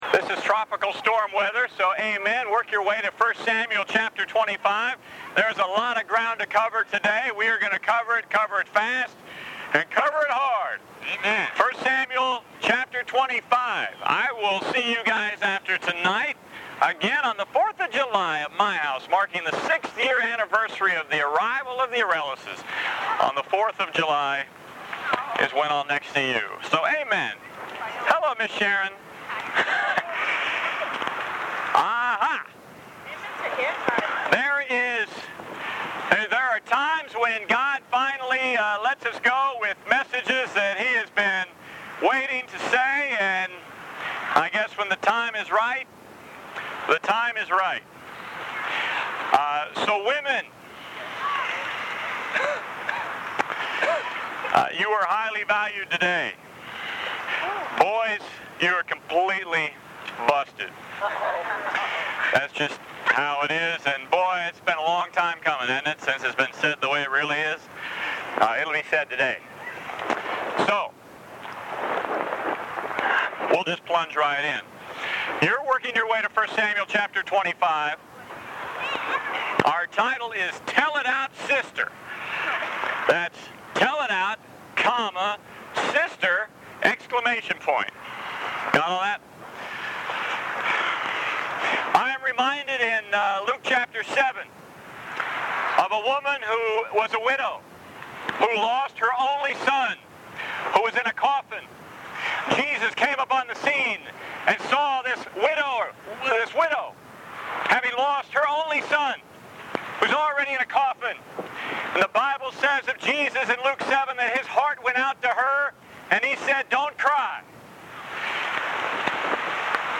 This message features the reading and preaching of the following Scriptures: 1 Samuel 25:22-25 Esther 4:13-16 Luke 8:3 Luke 24:9-11 Genesis 2:18, 20, 23-24 Colossians 2:6-7 Instructions: To download on a Mac, control-click the message link below and select a download option.